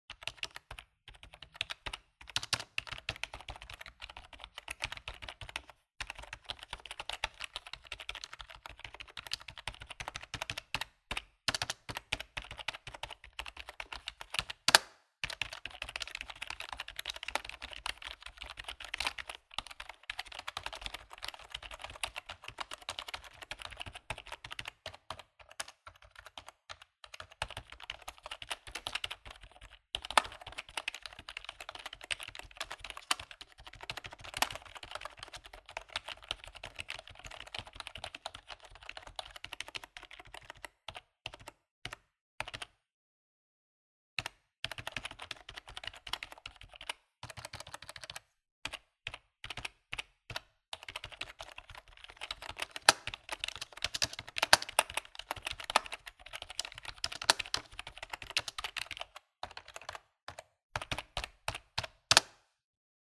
Download Typing sound effect for free.
Typing